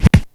030-HH_Push_01.wav